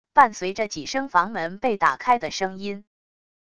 伴随着几声房门被打开的声音wav音频